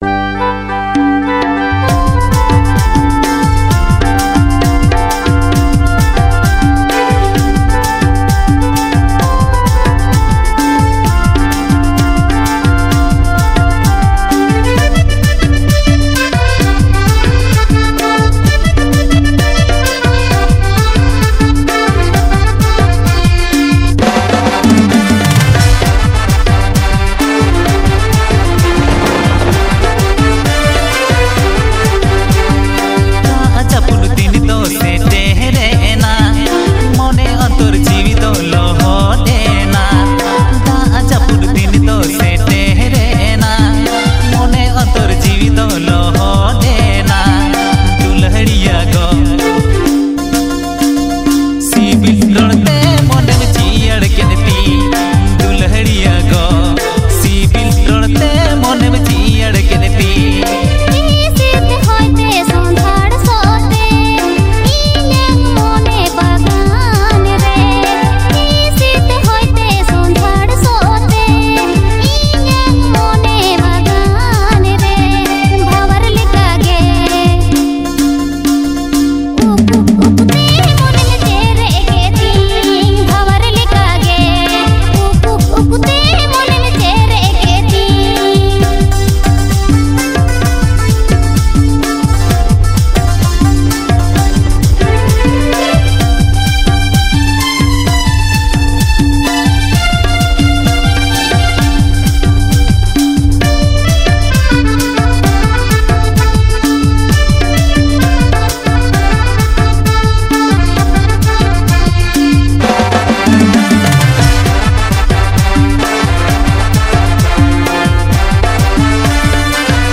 Santali song
• Male Artist